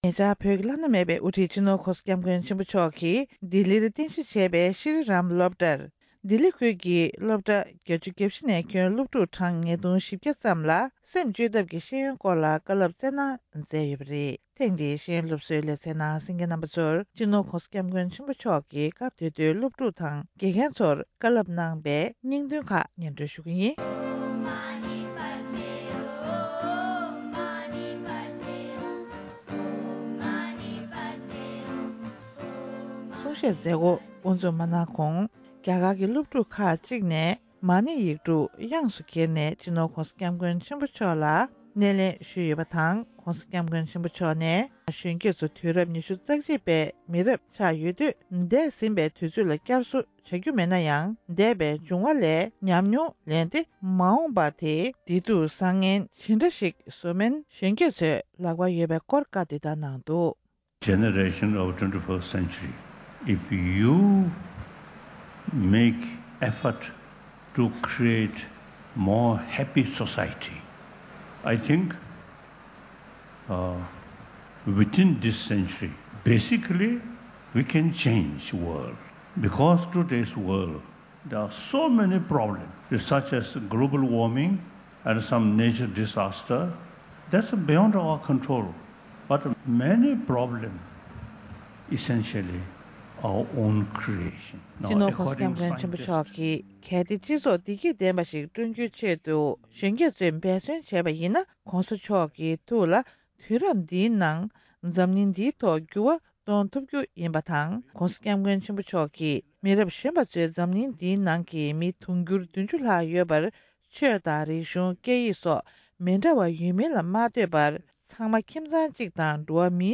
རྒྱ་གར་ལ་གནའ་དེང་ཟུང་འབྲེལ་གྱི་ཐོག་ནས་ཤེས་ཡོན་སློབ་འཁྲིད་བྱ་ཐུབ་པའི་ནུས་པ་ཡོད། ༢༠༡༩།༩།༢༠།༧གོང་ས་༧སྐྱབས་མགོན་ཆེན་པོ་མཆོག་ནས་རྒྱ་གར་རྒྱལ་ས་ལྡི་ལི་རུ་ཡོད་པའི་ཤྲི་རམ་སློབ་གྲྭའི་ནང་ཆིབས་བསྒྱུར་དང་བཀའ་སློབ་བསྩལ་གནང་མཛད་ཡོད་པ།